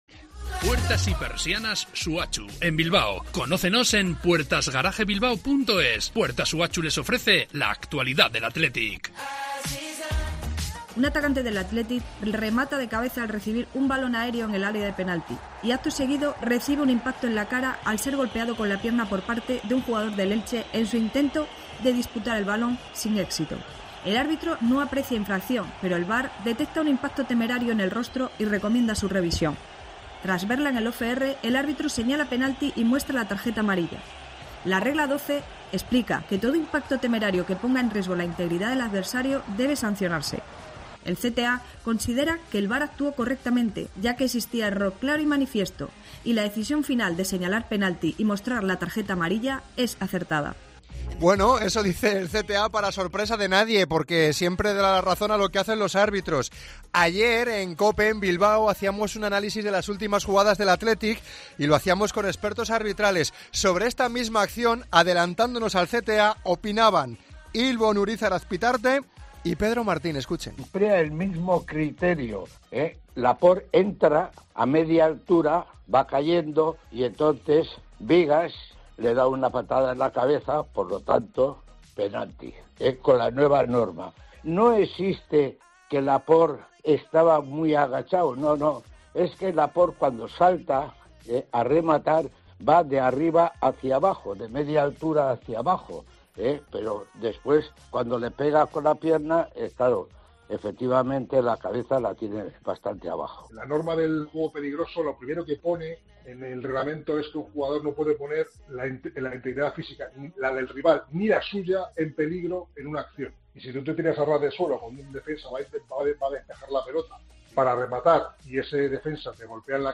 Debate entre expertos